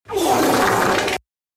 Fart Meme Sound Better Sound Button: Unblocked Meme Soundboard